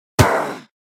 Dźwięk śmierci wiedźmy nr.3
Witchdies3.wav